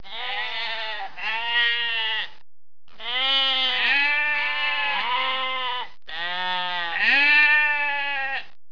جلوه های صوتی
دانلود صدای حیوانات جنگلی 56 از ساعد نیوز با لینک مستقیم و کیفیت بالا